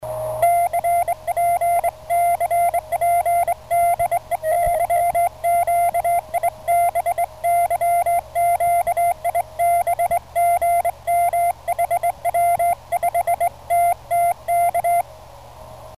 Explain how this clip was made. As a consolation, I attach a recording of a CP addressed broadcast by Romania tanker ship "Fundulea" / YQIB which tragically was later